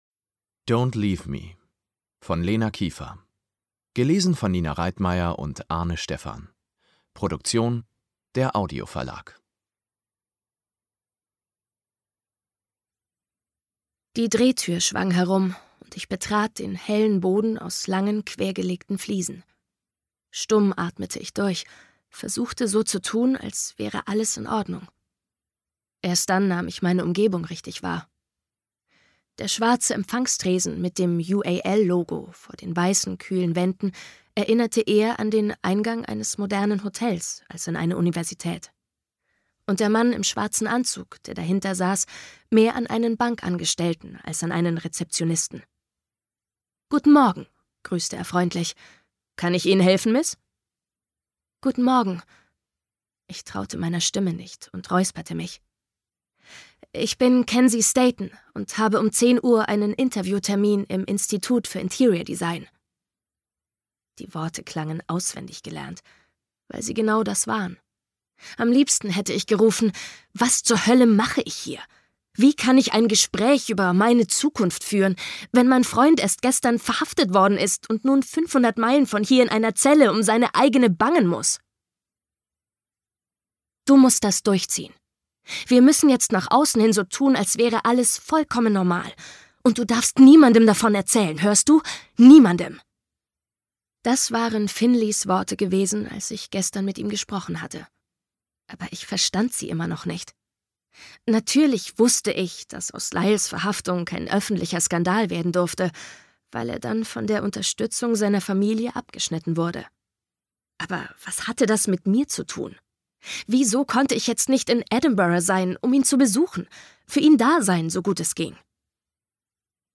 Don't LEAVE me (Teil 3) Ungekürzte Lesung